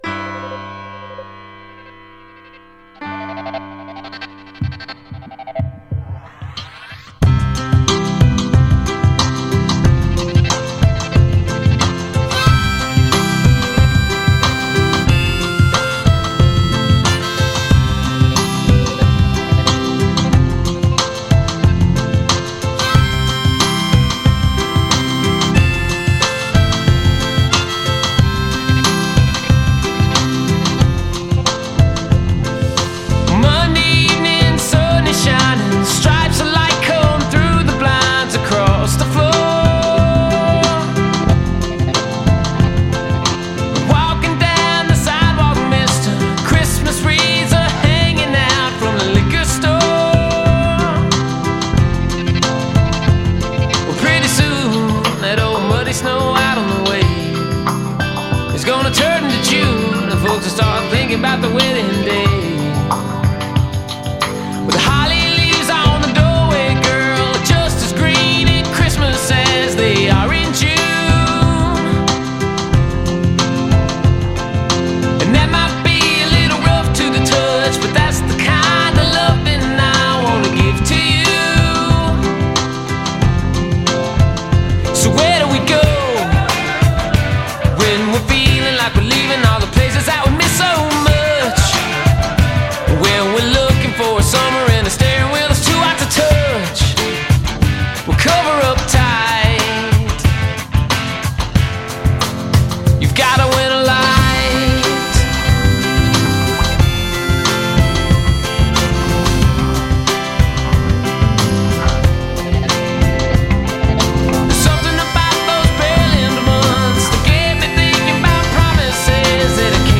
alt country band